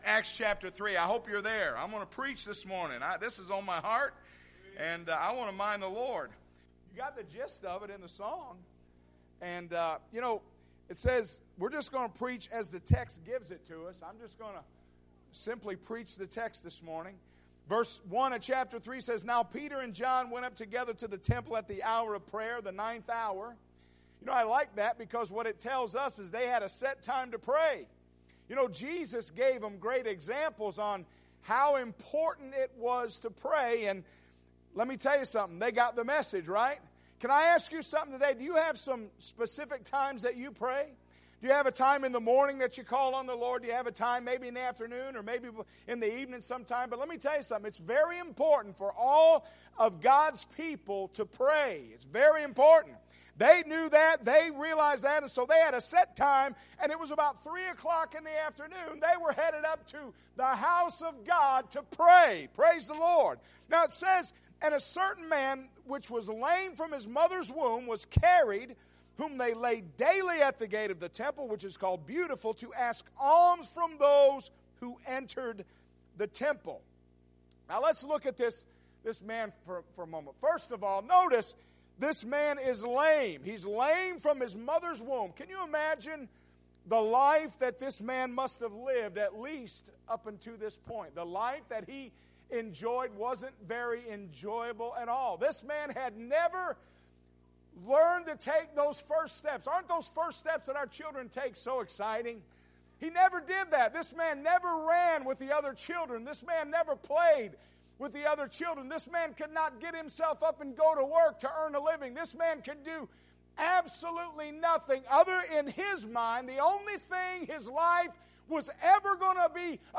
Sunday Morning - 03/12/2017 — Unity Free Will Baptist Church